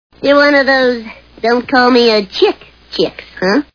The Simpsons [Bart] Cartoon TV Show Sound Bites